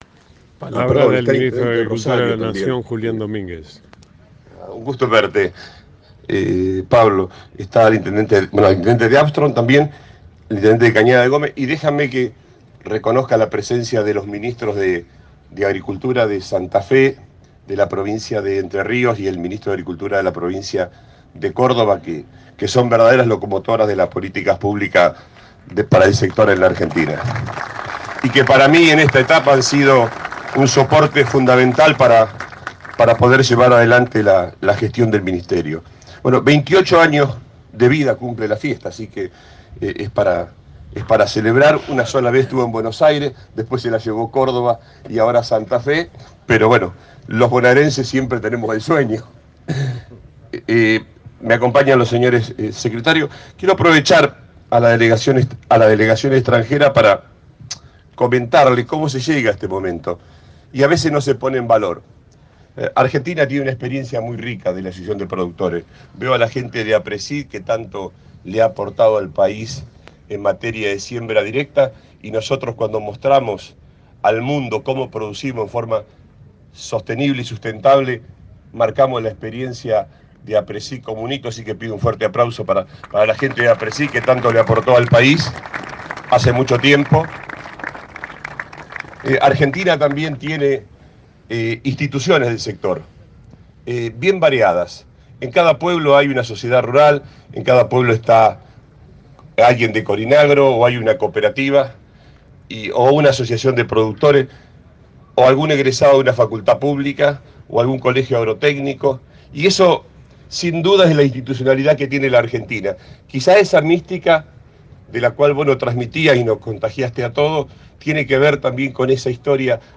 La inauguración de la 28 edición de AgroActiva convocó emociones, recuerdos, abrazos, encuentros y reencuentros frente al pórtico de entrada de la megamuestra.
Julián Domínguez Minístro de Agricultura, Ganadería y Pesca de la Nación